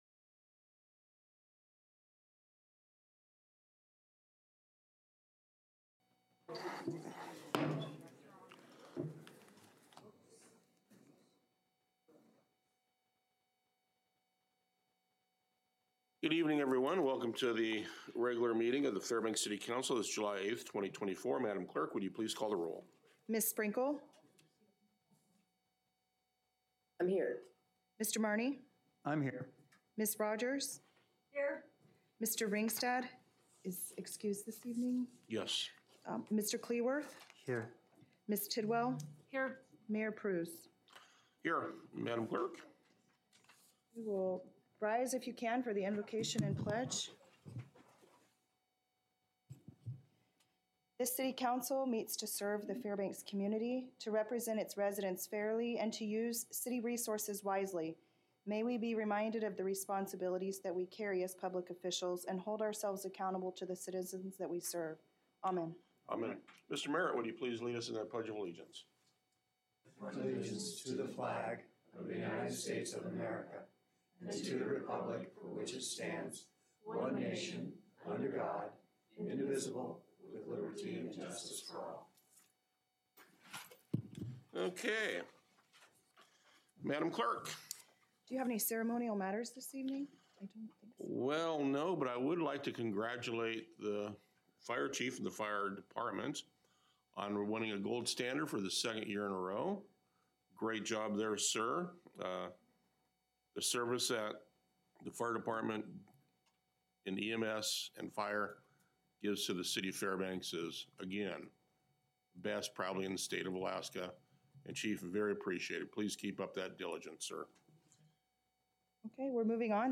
Regular City Council Meeting